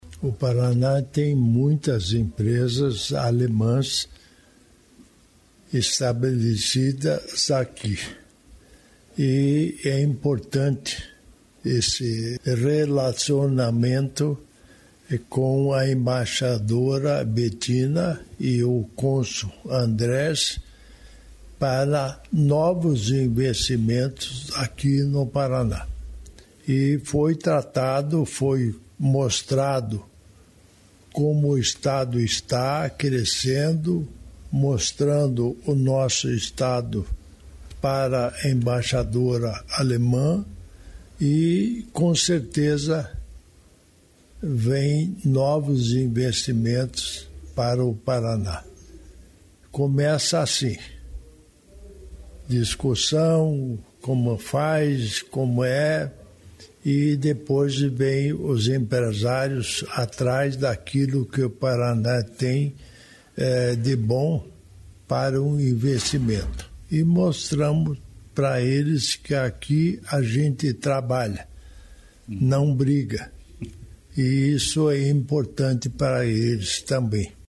Sonora do vice-governador, Darci Piana, sobre a parceria entre o Estado e a Alemanha